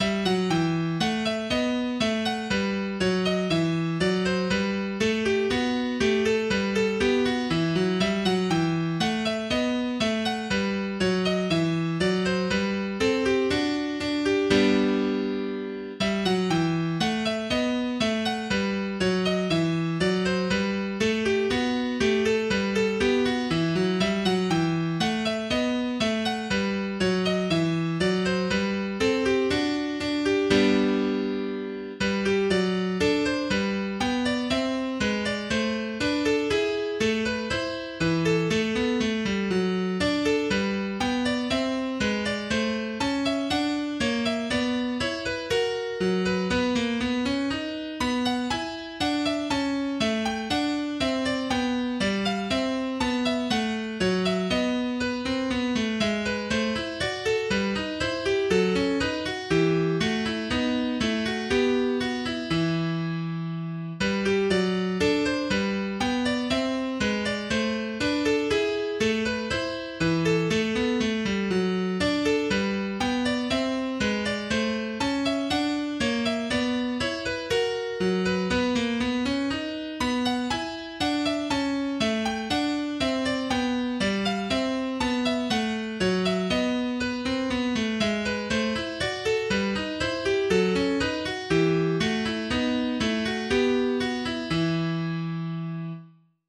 acaLead Notation - 20 mehrstimmige klassische Gitarrenstücke über dem vierten Bund
Bourree In Em - Bach >4 Org + Tab.mp3